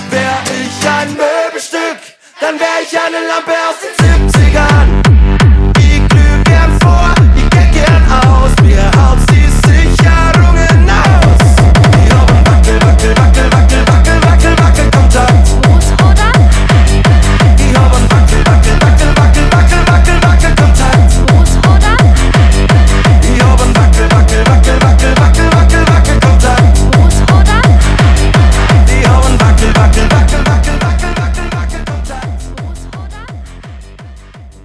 Gattung: Schlager für Blasorchester
Besetzung: Blasorchester
Tonart: g-Moll (B-Dur)